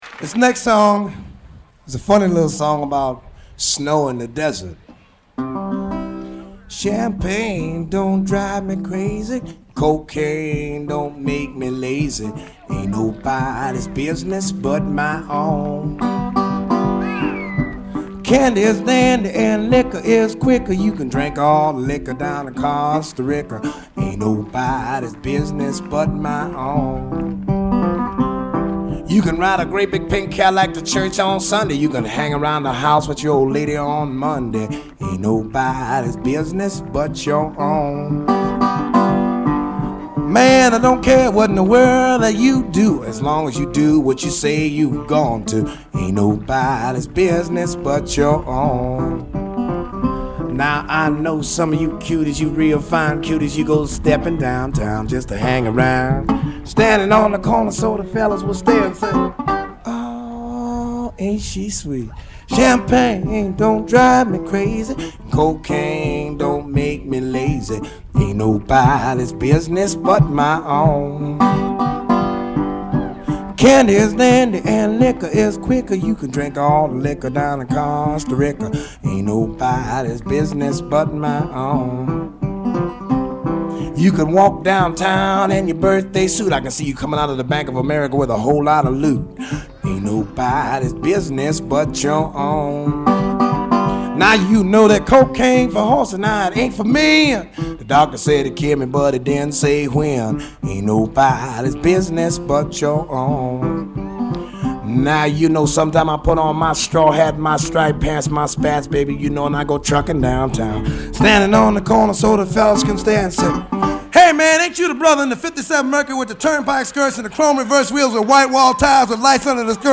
blues standard